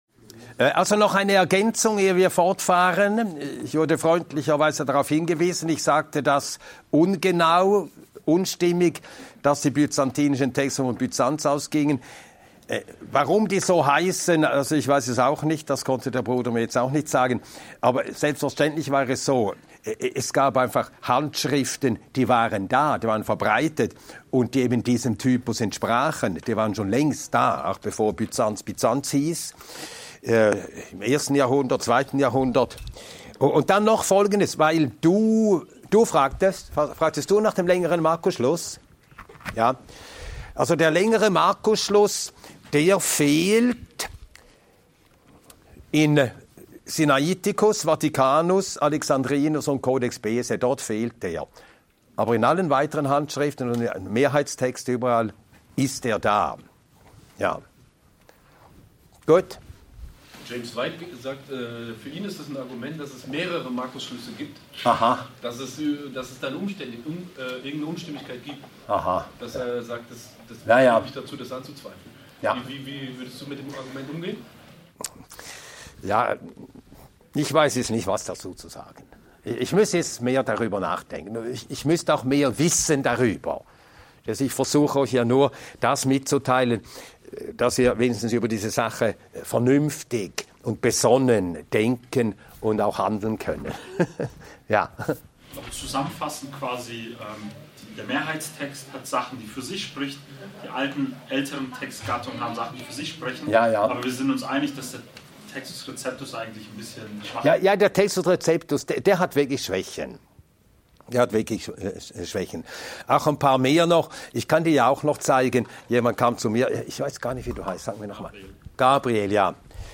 In diesem Vortrag erfährst du, wie du mit Textvarianten umgehen kannst, warum der Streit um den „einzig richtigen“ Text dich nicht verunsichern muss und was deine persönliche Verantwortung im Umgang mit der Bibel ist. Die zentrale Botschaft: Gottes Wort bleibt in seiner Kraft und Wahrheit bestehen – trotz menschlicher Fehler und textlicher Unterschiede.